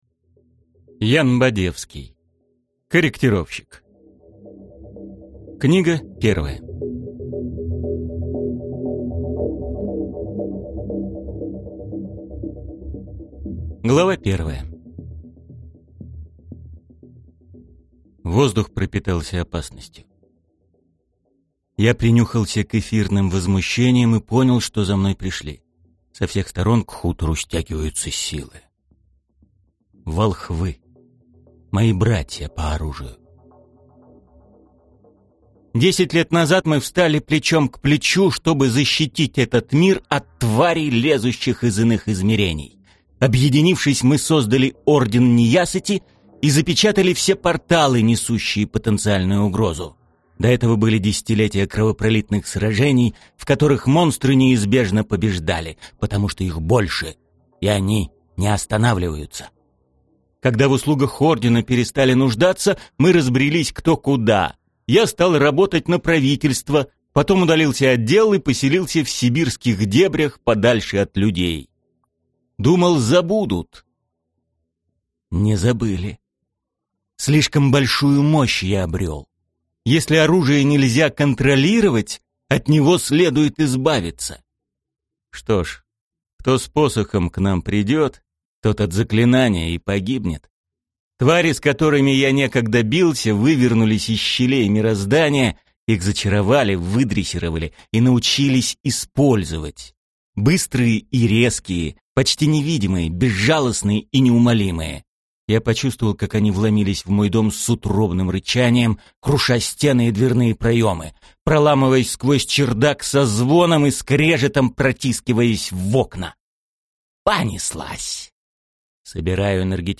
Аудиокнига Корректировщик | Библиотека аудиокниг